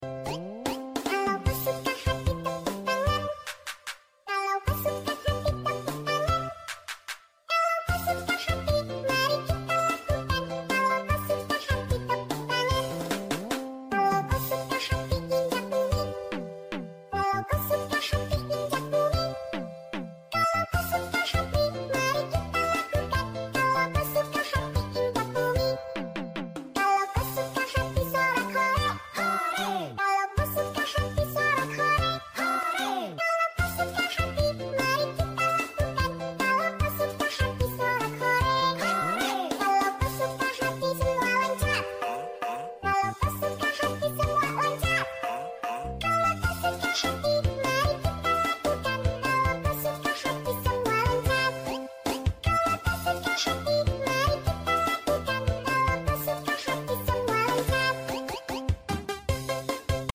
Cute Animals With Their Cute Sound Effects Free Download